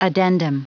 Prononciation du mot addendum en anglais (fichier audio)
Prononciation du mot : addendum